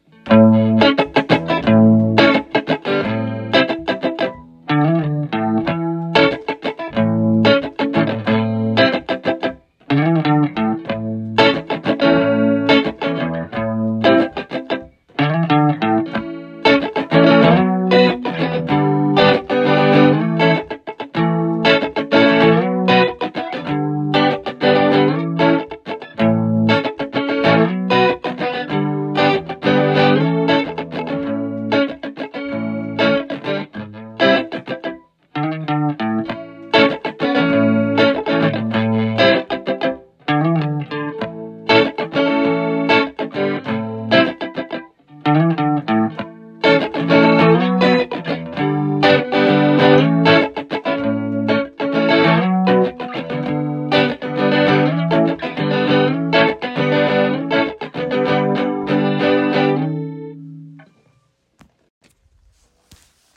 2026 Guitar ← Previous Next →